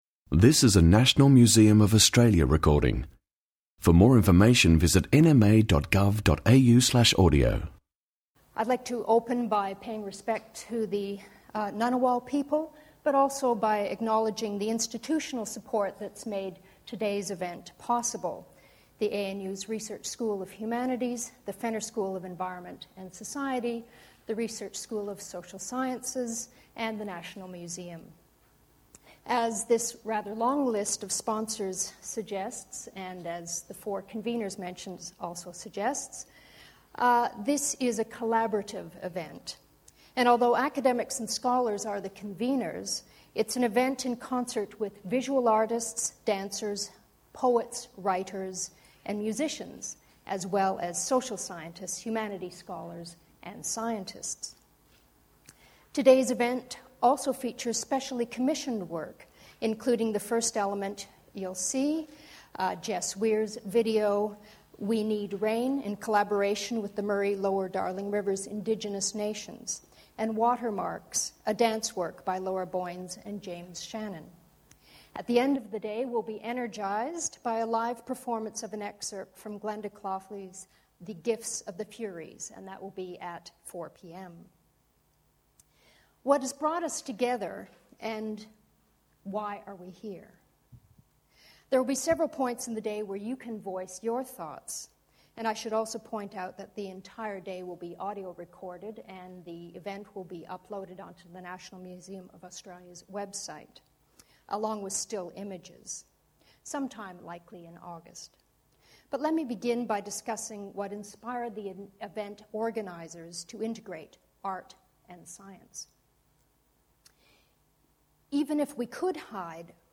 Fears around global warming are explored through different mediums by this panel comprised of artists, poets, dancers, singers, scientists, filmmakers, historians, creative writers and cultural theorists.